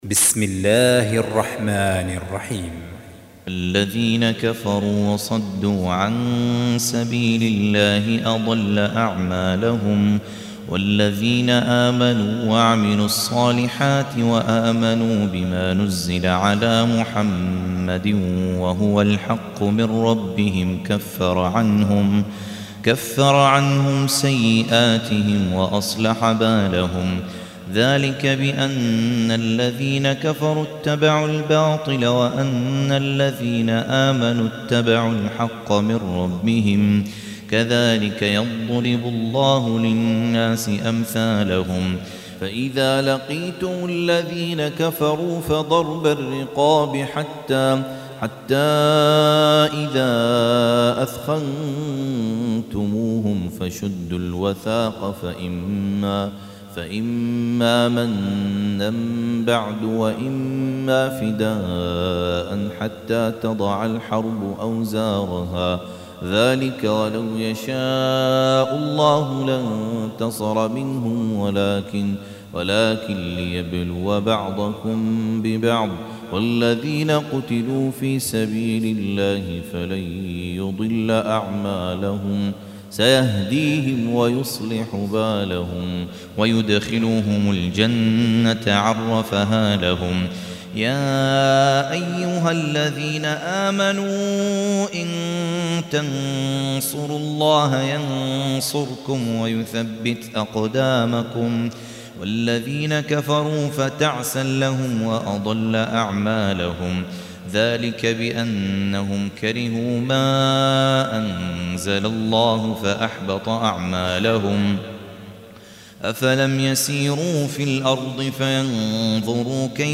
Surah Sequence تتابع السورة Download Surah حمّل السورة Reciting Murattalah Audio for 47. Surah Muhammad or Al-Qit�l سورة محمد N.B *Surah Includes Al-Basmalah Reciters Sequents تتابع التلاوات Reciters Repeats تكرار التلاوات